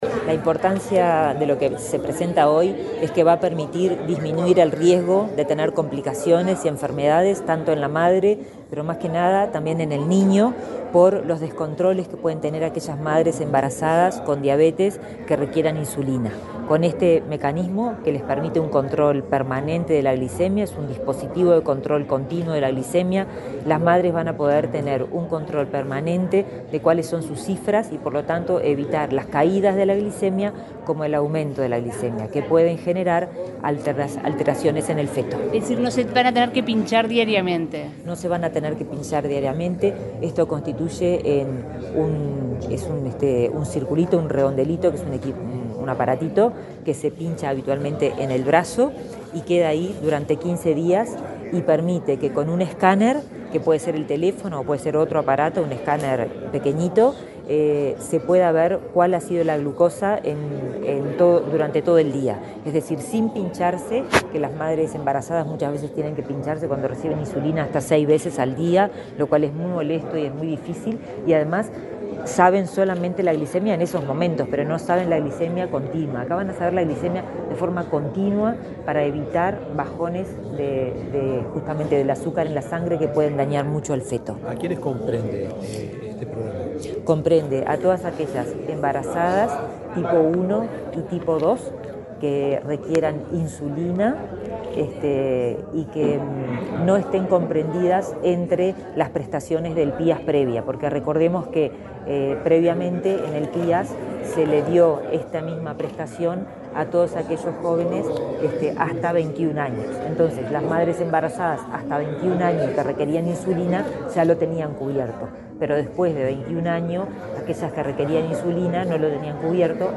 Declaraciones de la ministra de Salud Pública, Karina Rando
Este viernes 2 en la sede del Ministerio de Salud Pública, titular de la cartera, Karina Rando, dialogó con la prensa, luego de participar en el acto